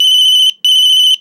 Tono de teléfono móvil 9